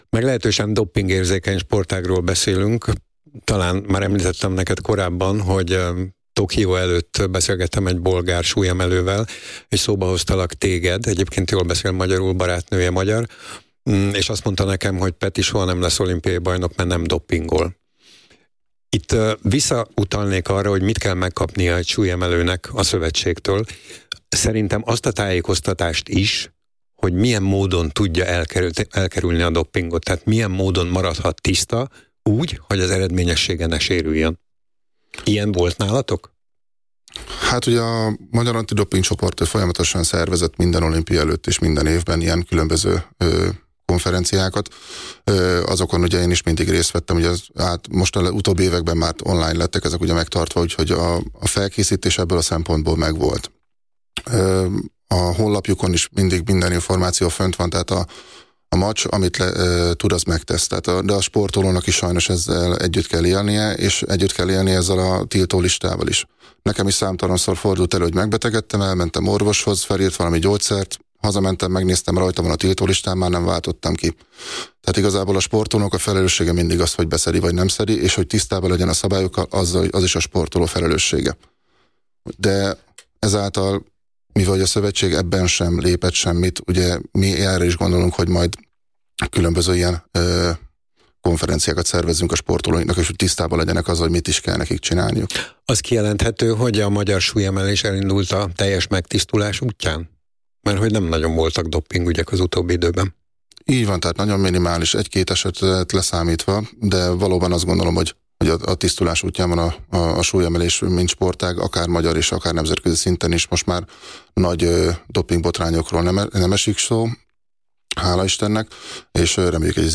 A Nemzeti Sportrádióban március 11-én megjelent riportműsorban Nagy Péter hangsúlyozta, hogy mennyire fontos, hogy a fiatal sportolók széleskörű doppingellenes felvilágosítást kapjanak, megismerjék a szabályokat, a jogaikat és kötelezettségeiket, tudják hogy kell használni a tiltólistát, annak érdekében hogy ne kövessenek el - nem szándékos - doppingvétséget. A sportoló kiemelte, hogy az elmúlt évek során Magyar Antidopping Csoporttól teljeskörű tájékoztatást kapott, nem csak az olimpiákat megelőző előadásokon, hanem a honlapunkon keresztül is.